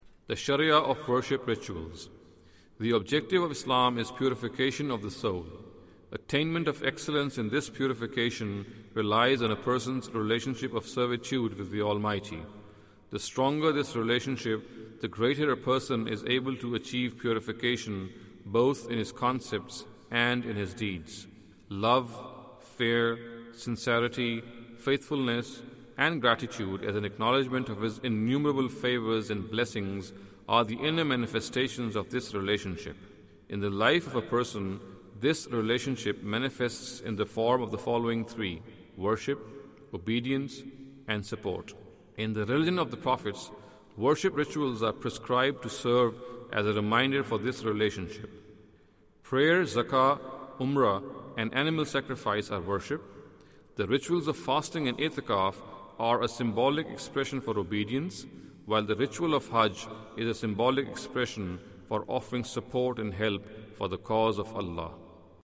Category: Audio Books / Islam: A Concise Introduction /